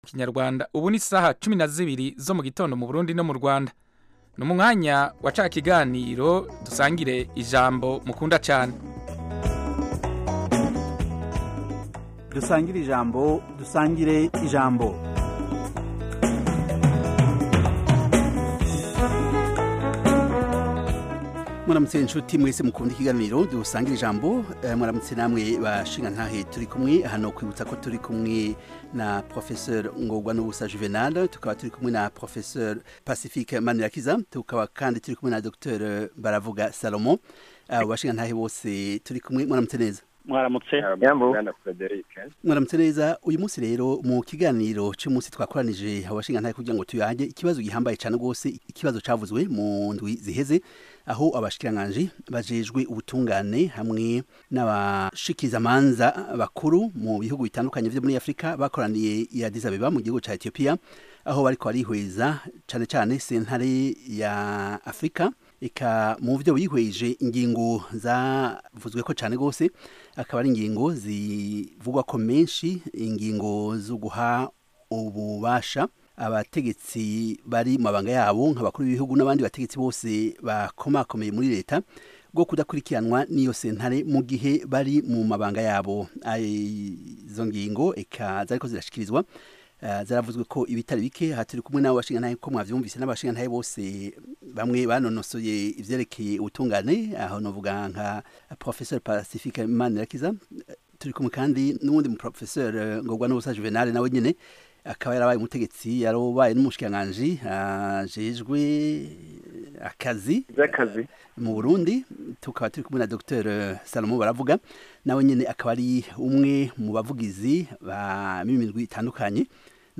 Dusangire-ijambo - Panel discussion and debate on African Great Lakes Region, African, and world issues